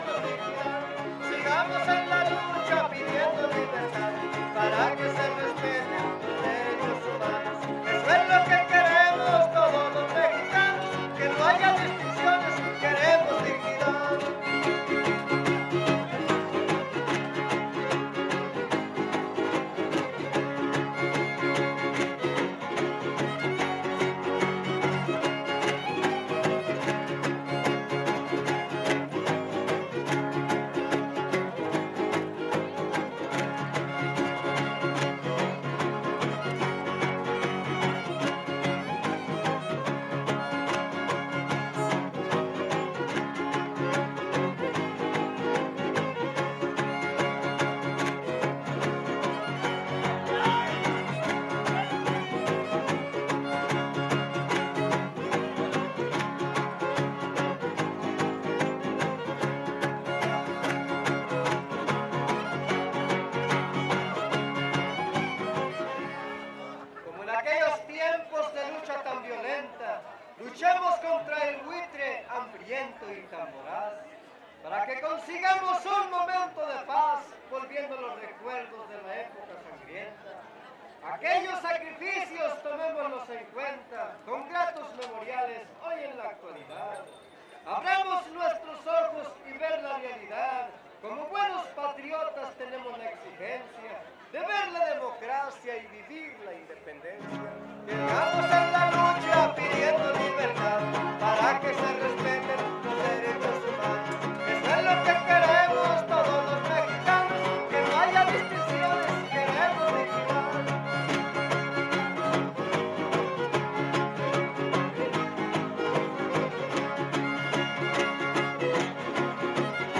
Huapango arribeño
Décima
No identificado (violín segundo)
Guitarra Violín Vihuela
Topada ejidal: Cárdenas, San Luis Potosí